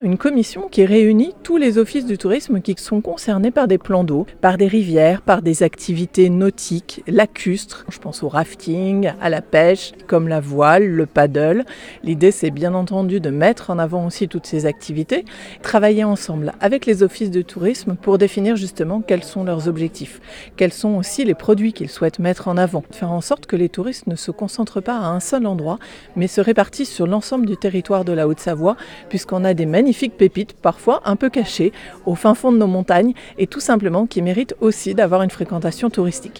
Précisions de la conseillère départementale Patricia Mahut, membre du comité de tourisme.